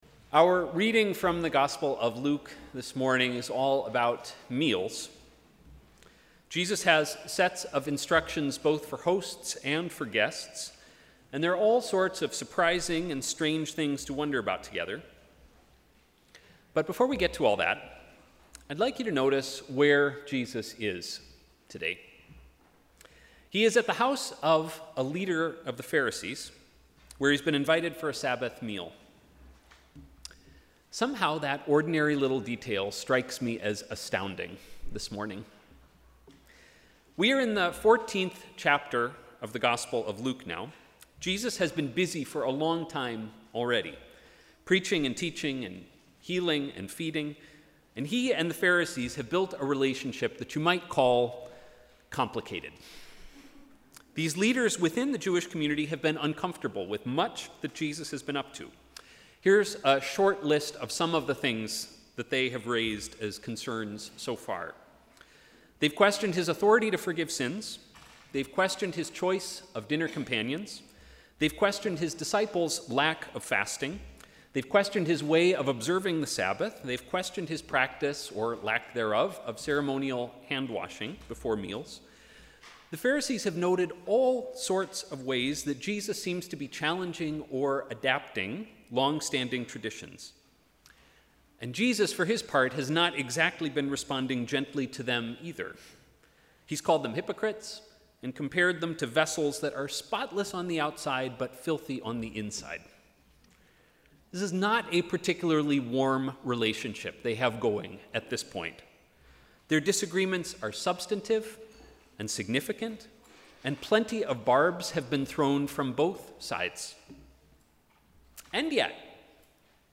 Sermon: ‘Table talk’